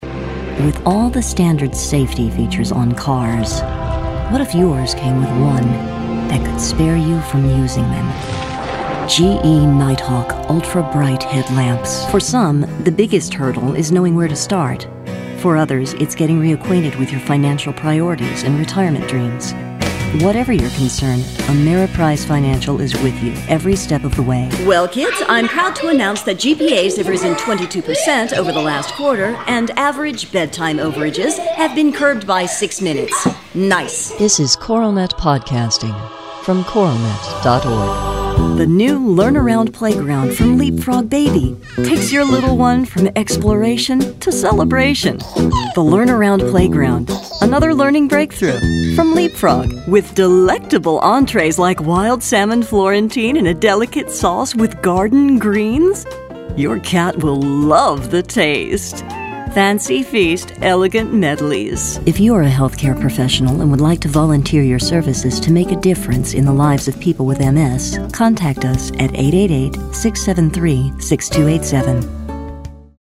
Experienced female voice artist with a rich, textured sound, authoritative yet warm
mid-atlantic
Sprechprobe: Werbung (Muttersprache):